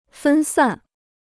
分散 (fēn sàn): to scatter; to disperse.